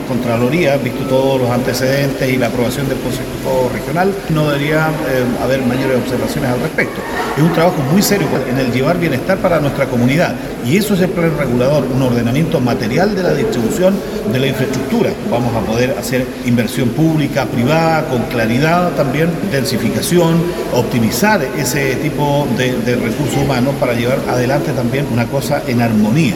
Al respecto, el alcalde de la comuna, César Crot, afirmó que el próximo paso será que el proyecto tome razón en la Contraloría.
07-09-alcalde-purranque.mp3